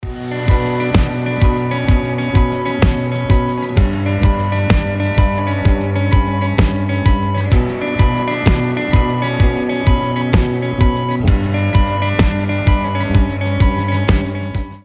• Качество: 320, Stereo
без слов
Проигрыш, начало песни без слов